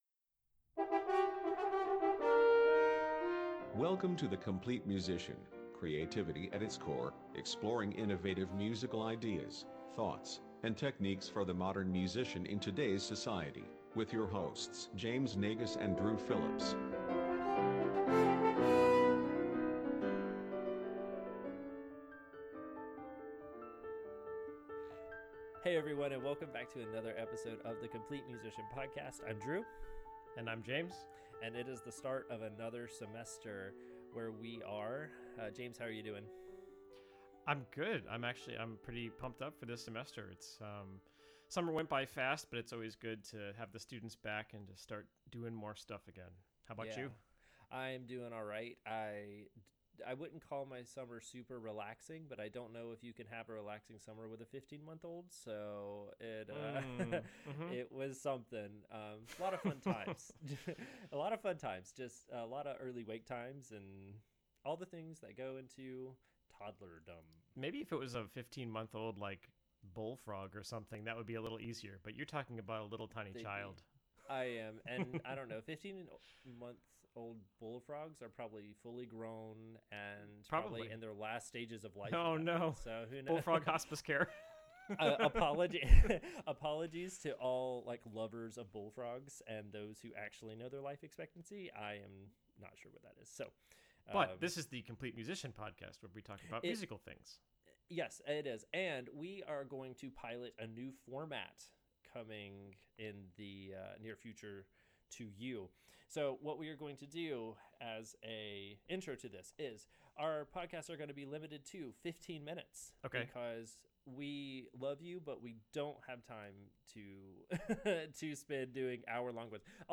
Completely ignore the poppy audio and obnoxious refrigerator hum during entire the show…it’s been a while B’OKAY?!?